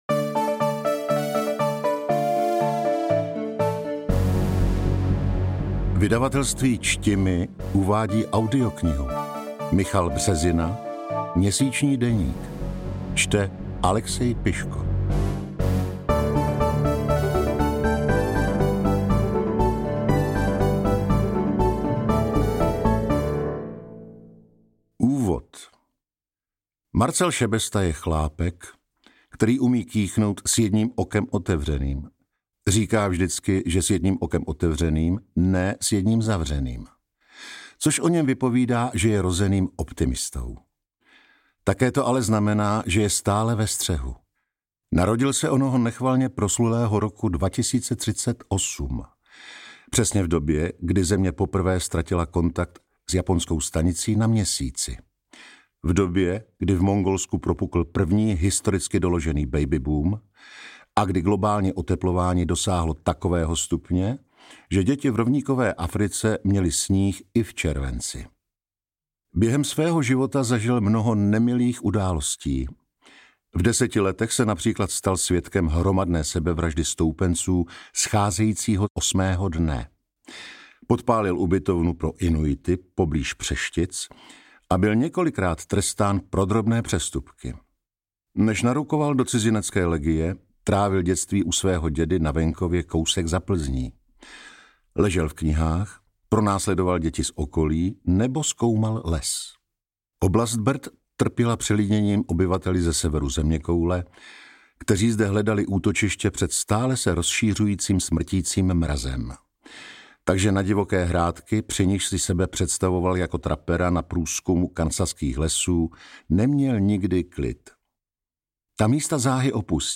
Audiokniha Měsíční deník je autentický deník zachráněný součinem vskutku absurdních okolností, ve kterých hraje roli obyčejný rohlík, neviditelný mravenec, kapesní stroj času a spousta dalších předmětů, jež se normálně válejí na Měsíci.